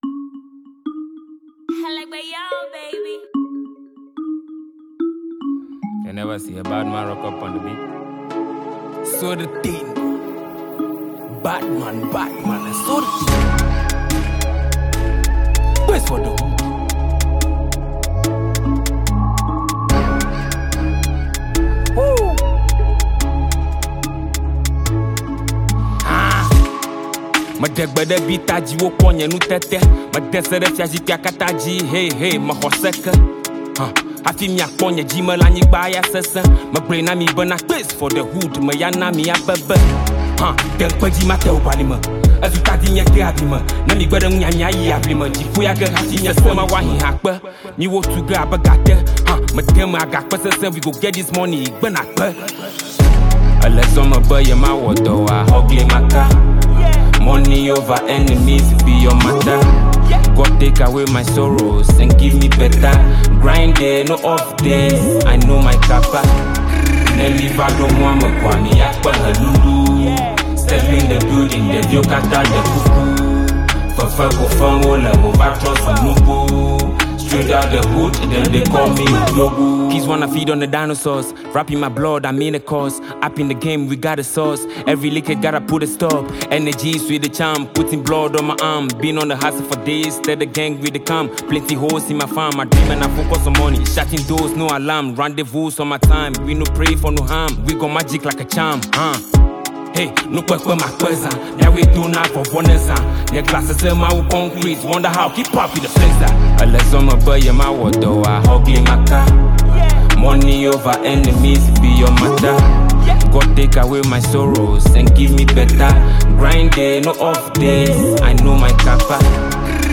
This isn’t just rap , it’s motivation and spiritual.